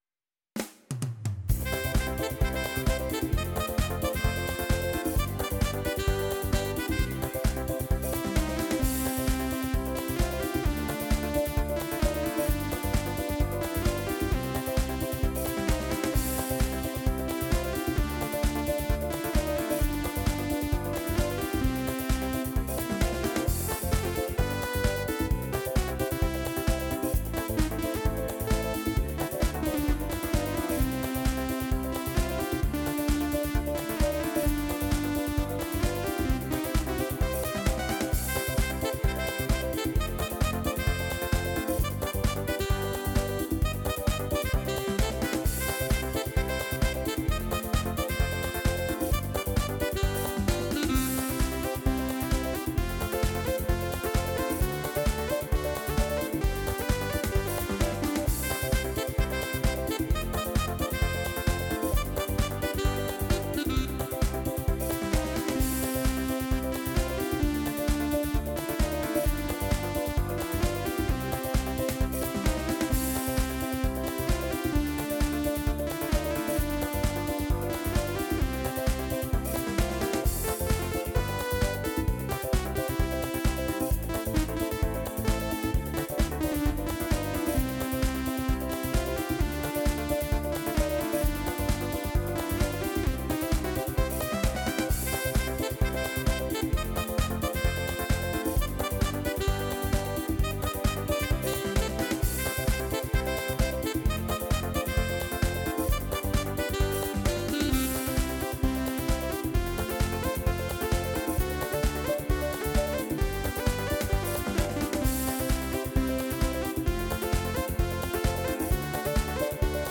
Disco-Fox